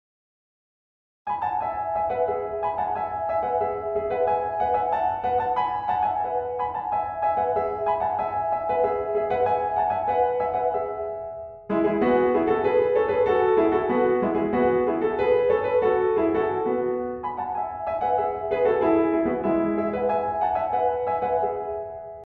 リズミカルなアレグロの要素を持ちながらも、包み込むような穏やかさが際立っていますよね。
・演奏の速さは♩=９０くらい（ざっくり、アレグレッとの速さは９０〜１２０あたりの数値で示される）
ほどよくゆっくり、かつほどよく速く。心地よい中間のアンニュイを楽しむことができます。